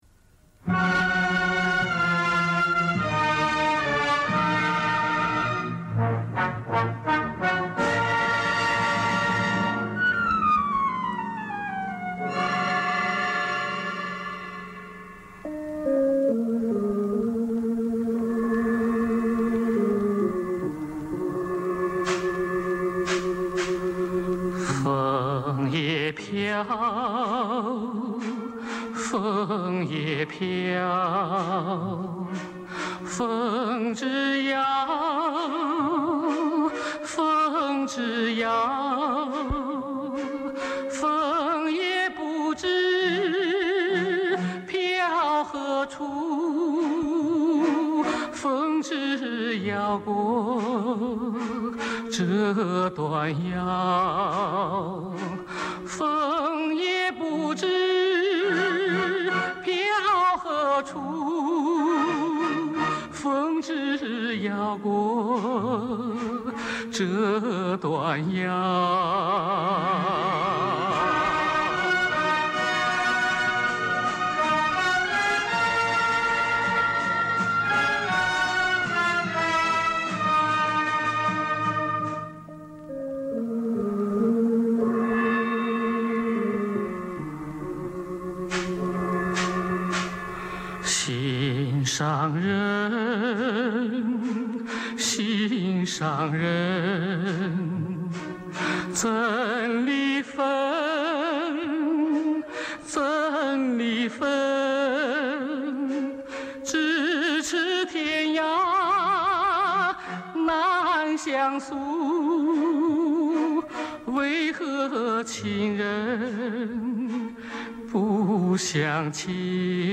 （两首插曲连续播放）